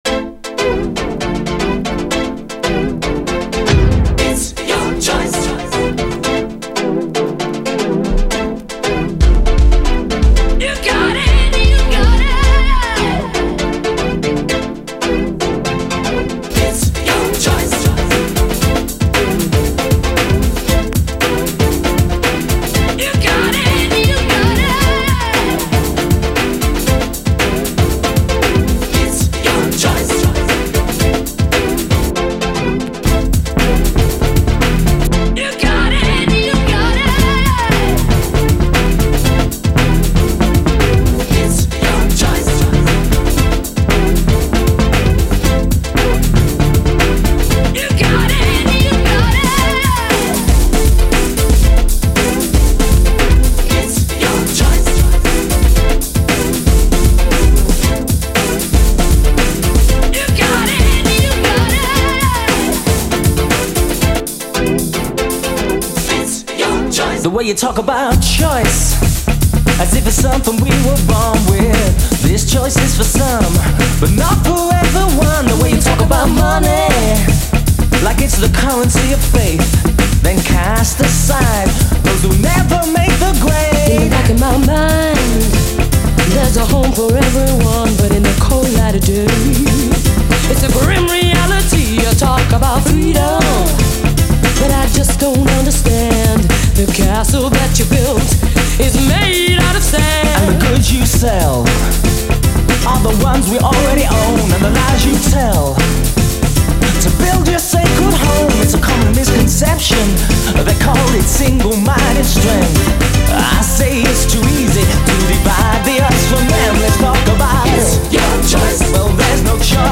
SOUL, DISCO, 70's～ SOUL, HIPHOP, DANCE
そんなコラボがあったのか、という感じの組み合わせによるグラウンド・ビート〜初期ハウス・トラック！